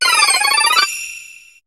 Cri de Korillon dans Pokémon HOME.